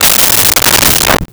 Desk Drawer Closed 02
Desk Drawer Closed 02.wav